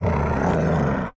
Minecraft Version Minecraft Version snapshot Latest Release | Latest Snapshot snapshot / assets / minecraft / sounds / mob / wolf / angry / growl2.ogg Compare With Compare With Latest Release | Latest Snapshot
growl2.ogg